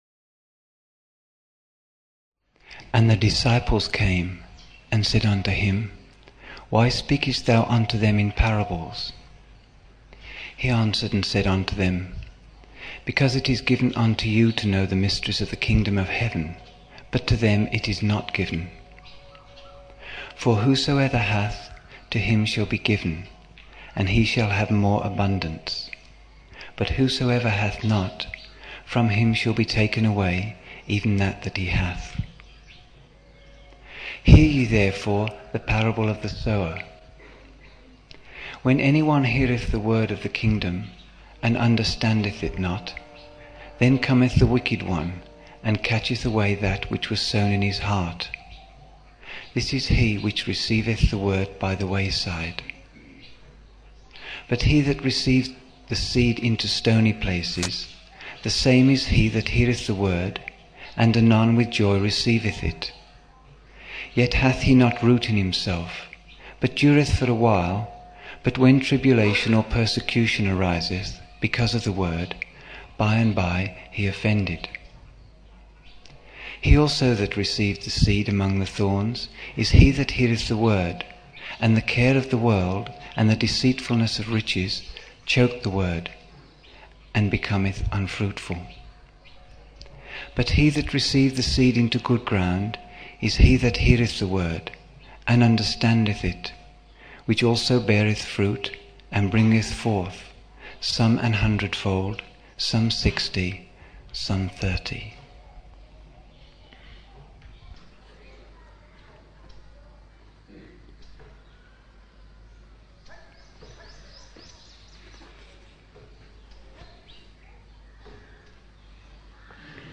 6 November 1975 morning in Buddha Hall, Poona, India